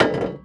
sfx_metal_bar.wav